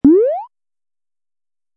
cartoon-jump-6462.wav